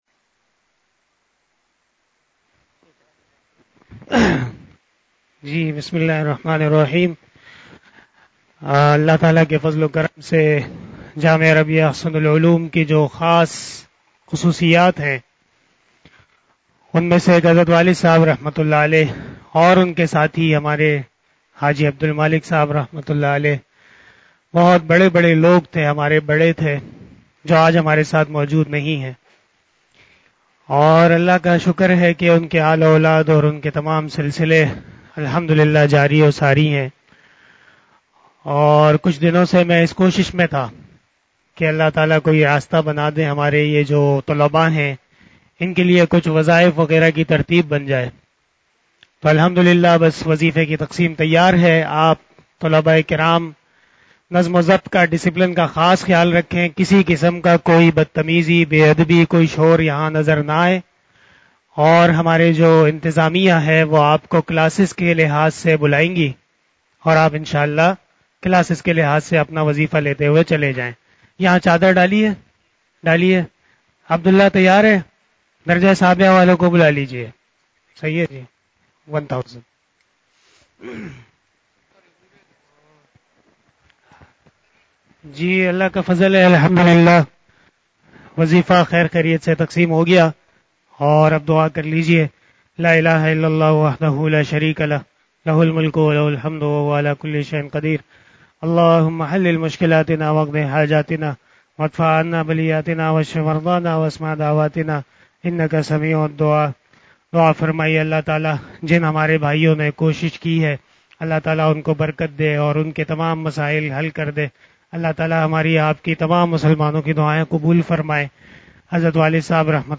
089 After Zuhar Namaz Bayan 05 October 2022 (08 Rabi ul Awwal 1444HJ) Wednesday